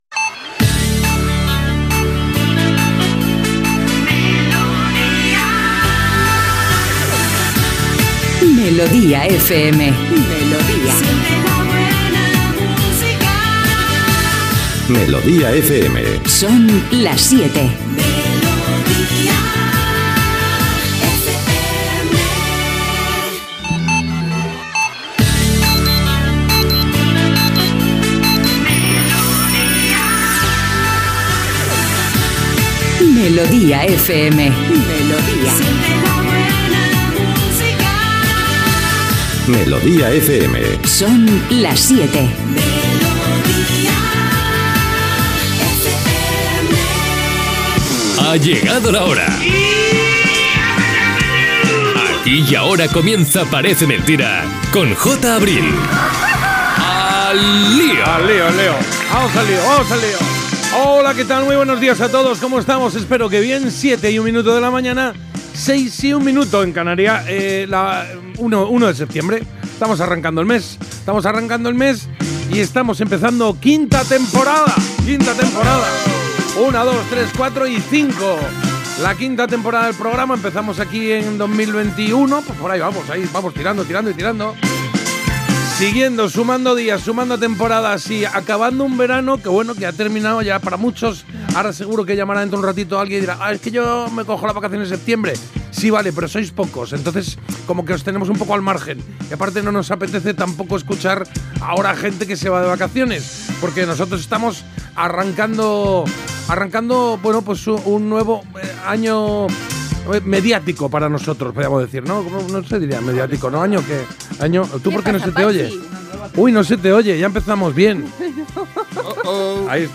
Indicatiu emissora, careta, hora, presentació amb esment que es tracta de la cinquena temporada. La fi de les vacances. Salutació a l'equip del programa. Les vancances que han fet
Entreteniment